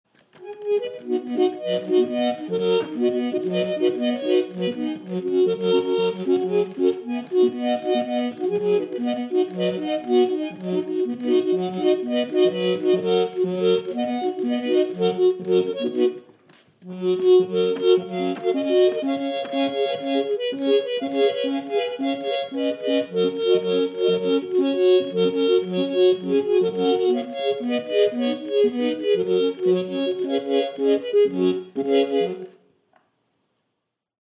Ariston à 16 touches présenté à l'exposition universelle de 1900.
La manivelle latérale fait tourner le disque et actionne le soufflet.
Musée de Musique Mécanique